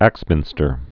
(ăksmĭnstər)